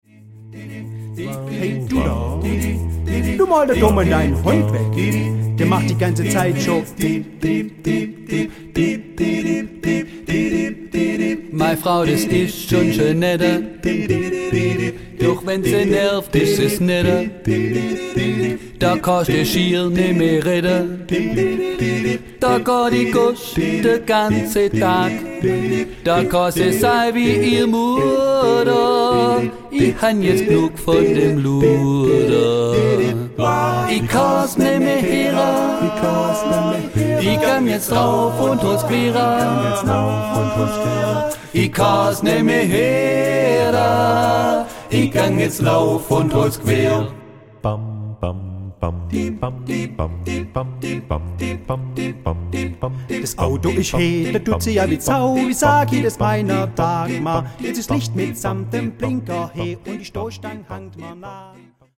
Studio-Tonträger